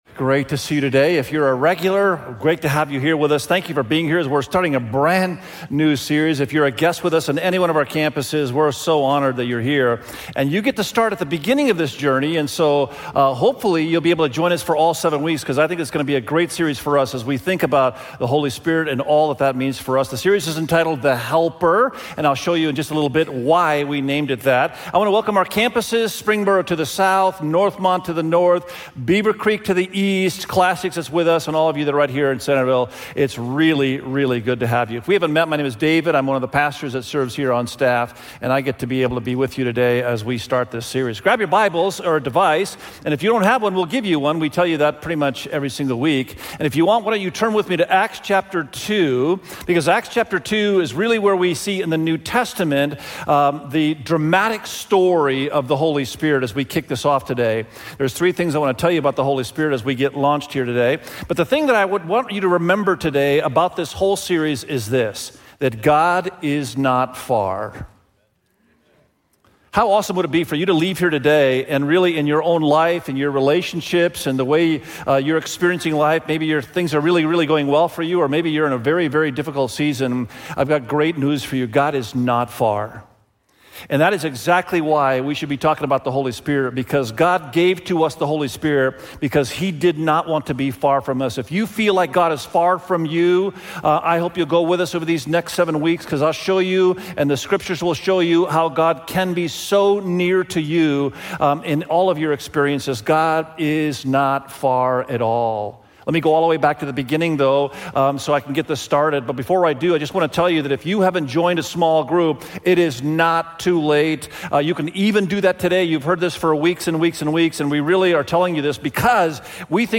God-is-Not-Far_SERMON.mp3